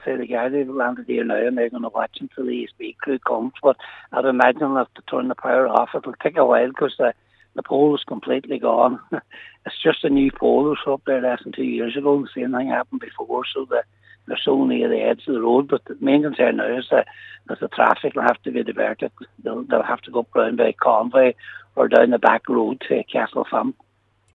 Local Cllr Patrick McGowan says gardaí are arriving, and the road is closed as a safety precaution: